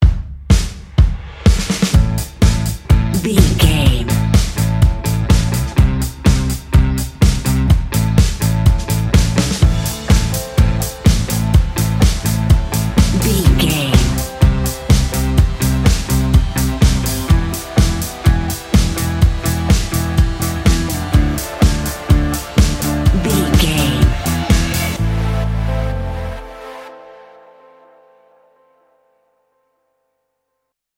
Fast paced
Aeolian/Minor
Fast
dark
edgy
electric guitar
aggressive
indie rock
electro
drums
bass guitar
synthesiser